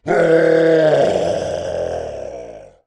Zombie Voices Demo
super_zombie_attack_2.wav